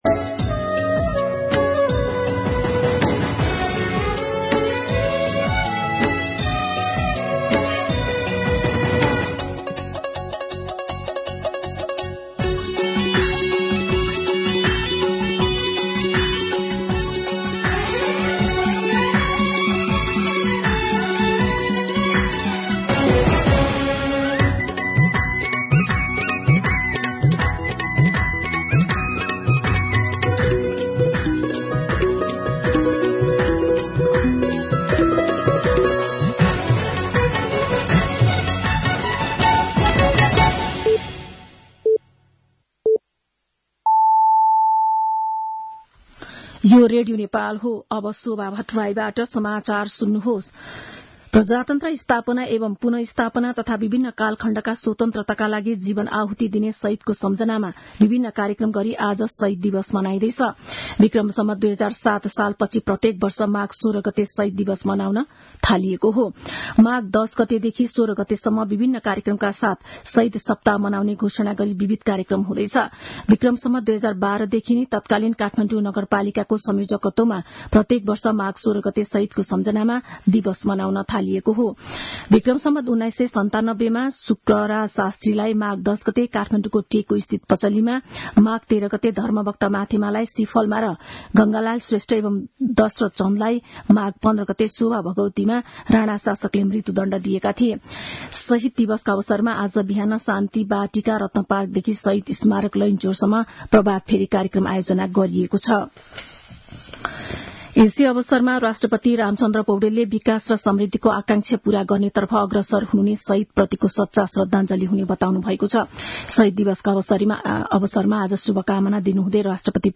मध्यान्ह १२ बजेको नेपाली समाचार : १७ माघ , २०८१